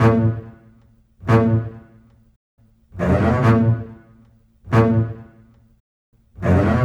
Exodus - Cello.wav